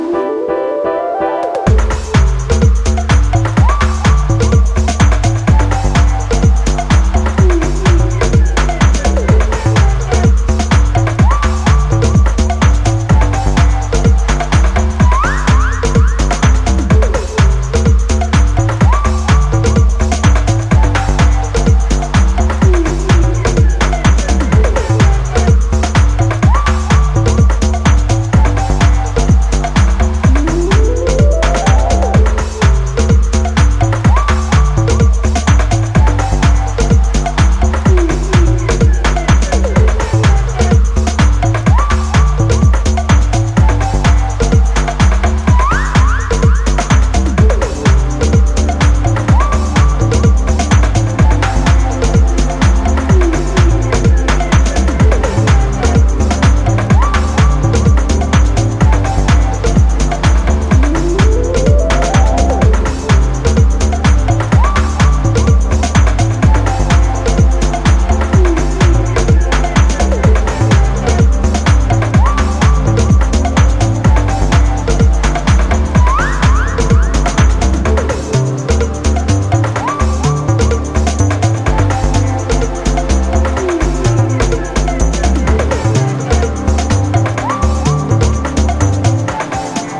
今回は、爽やかに心地良く高揚していくメロウでバレアリックなフィーリングのエレクトロニック・ハウスを展開。
ジャンル(スタイル) DEEP HOUSE